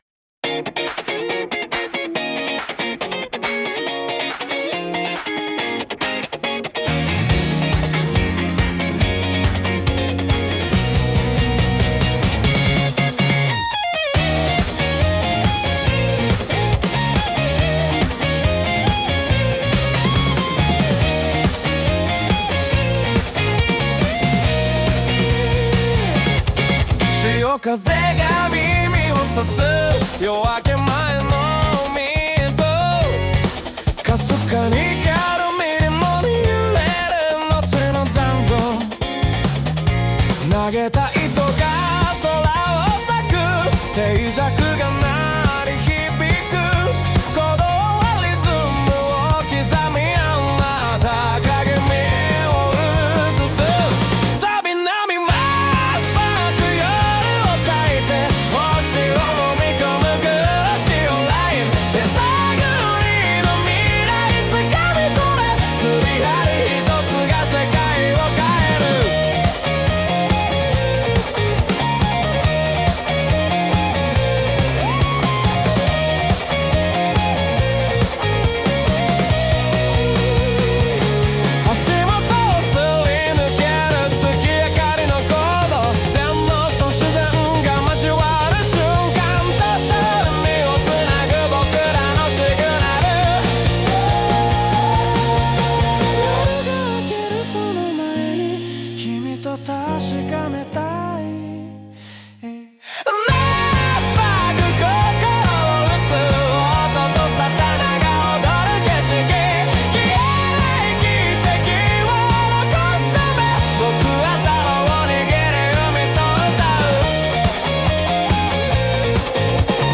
🎣 釣り歌：波間スパーク 今風メロディ！